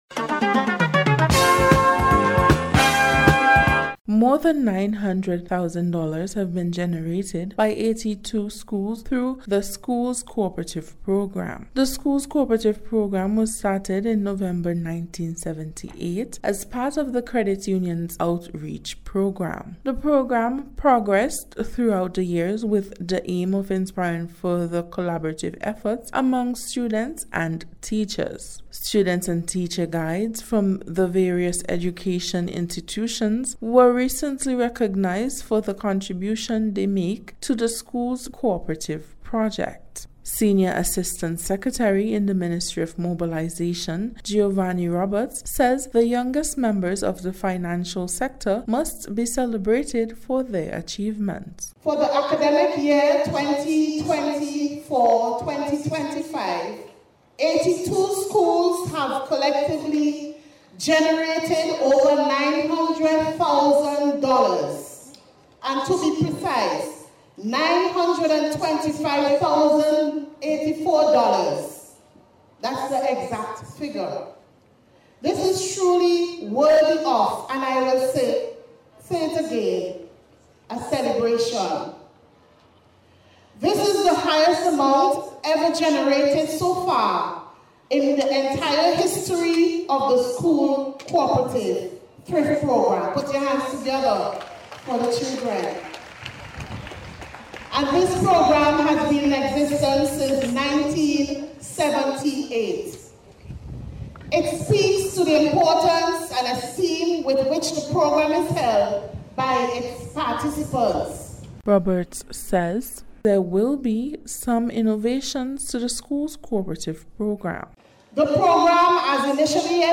SCHOOLS-COOPERATIVE-REPORT.mp3